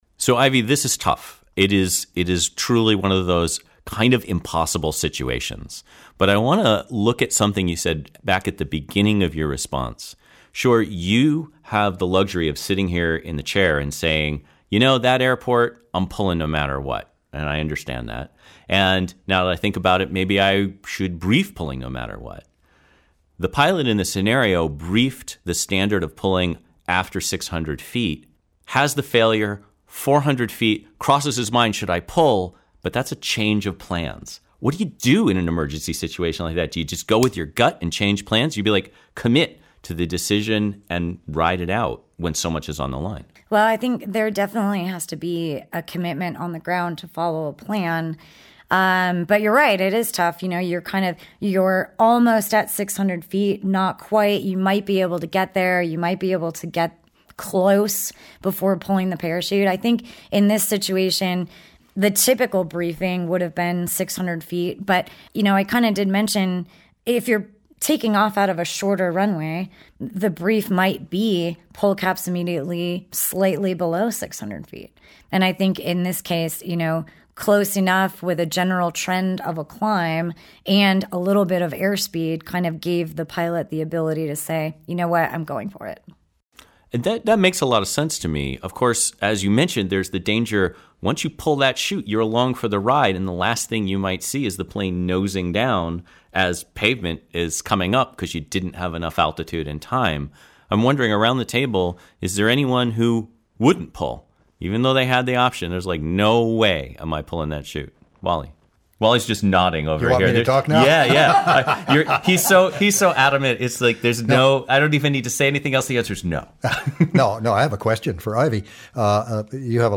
A-ruly_Short_trip_roundtable.mp3